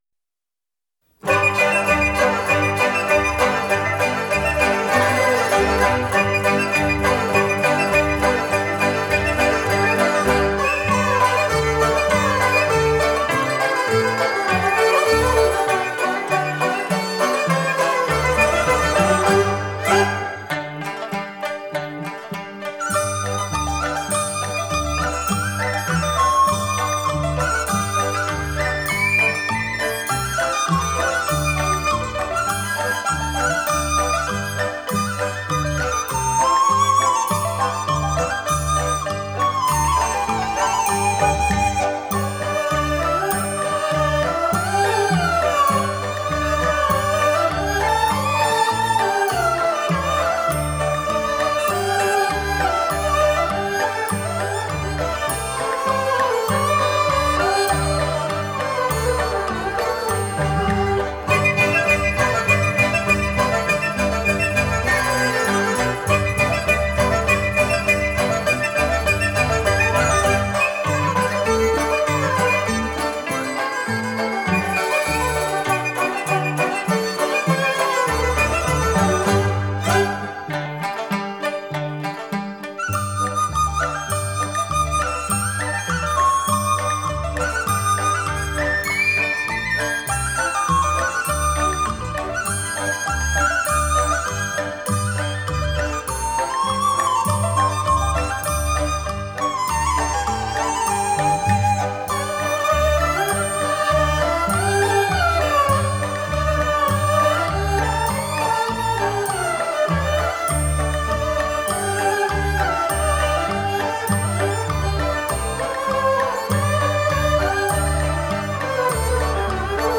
顶级民乐
一展喜气洋洋、轻快跳跃的乐思，勾划出欢天喜地、乐观向上的无限情怀